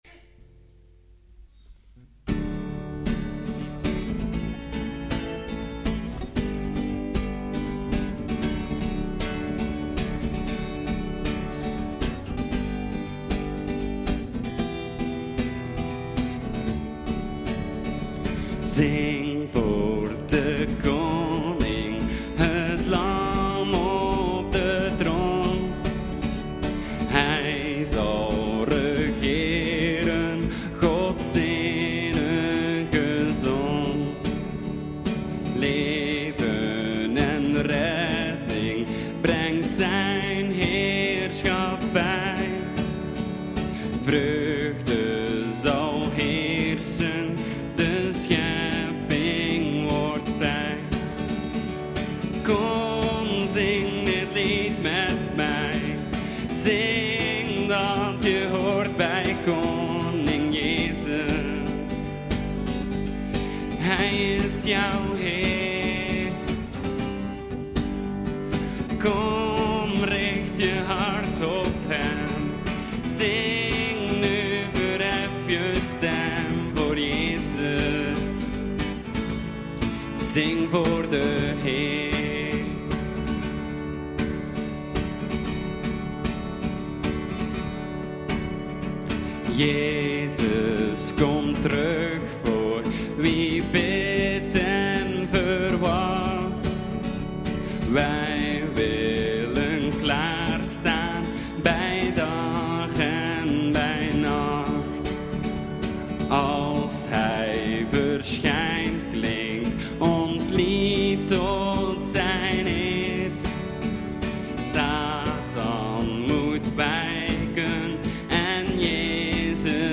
De Rijkdom in God ( Efeziërs 1:15-23 ) – Evangelische Kerk De Pottenbakker VZW